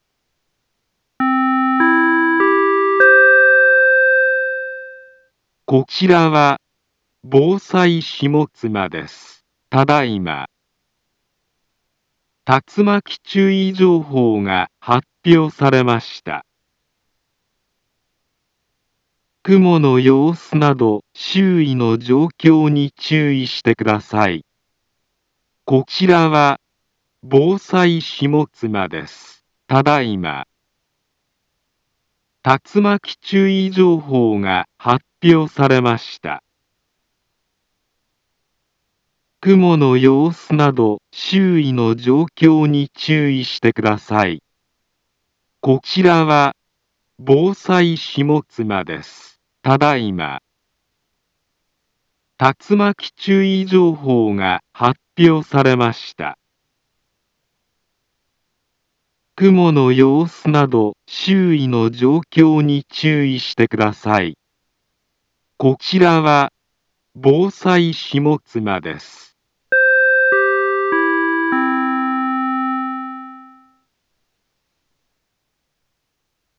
Back Home Ｊアラート情報 音声放送 再生 災害情報 カテゴリ：J-ALERT 登録日時：2021-05-02 14:15:23 インフォメーション：茨城県北部、南部は、竜巻などの激しい突風が発生しやすい気象状況になっています。